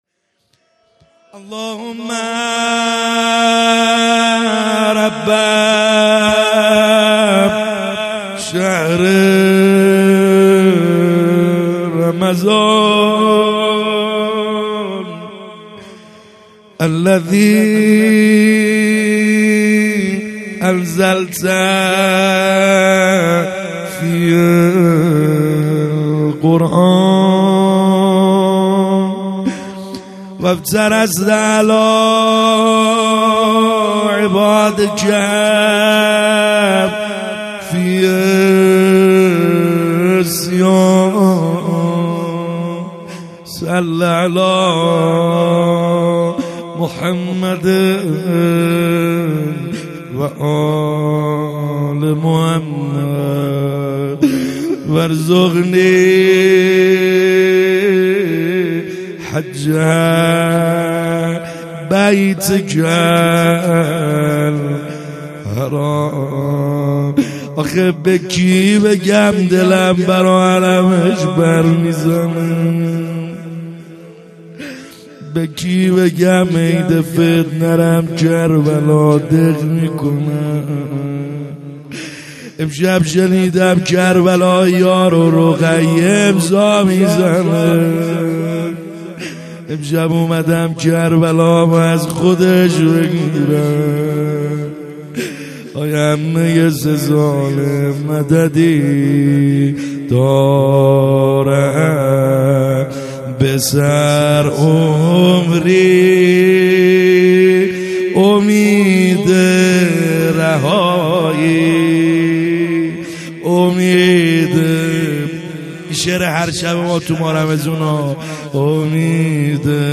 خیمه گاه - بیرق معظم محبین حضرت صاحب الزمان(عج) - مناجات | شب سوم